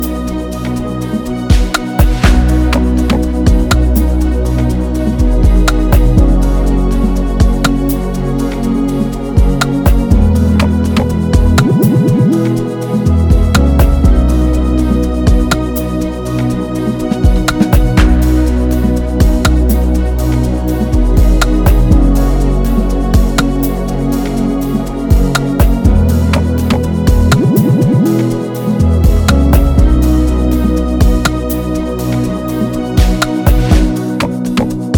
Afro-Beat